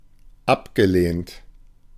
Ääntäminen
Synonyymit défraîchi Ääntäminen France Tuntematon aksentti: IPA: /fle.tʁi/ Haettu sana löytyi näillä lähdekielillä: ranska Käännös Ääninäyte 1. abgelehnt 2. zurückgewiesen 3. aussortiert Suku: m . Flétri on sanan flétrir partisiipin perfekti.